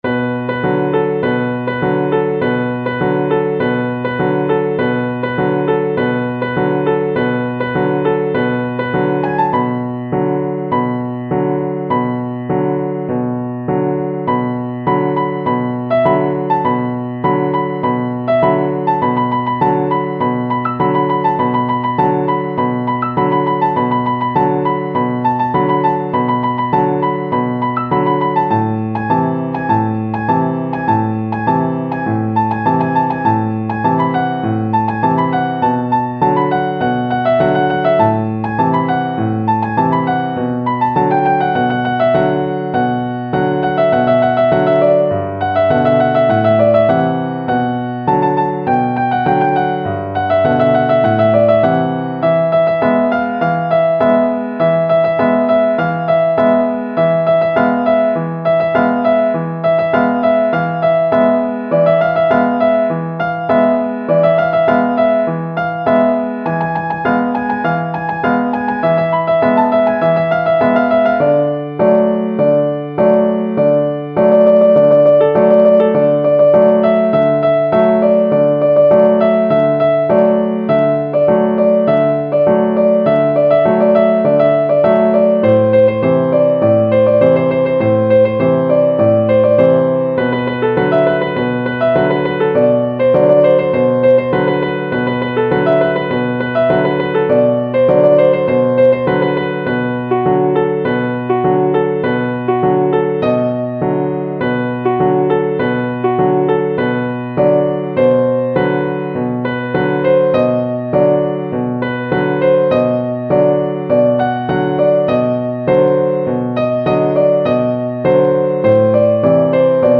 ساز : پیانو